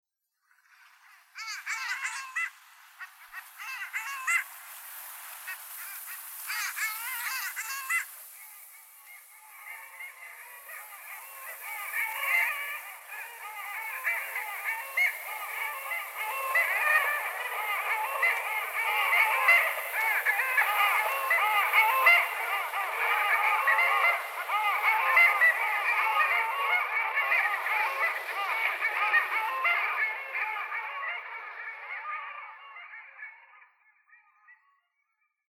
”Merilintujen” ääninäytteitä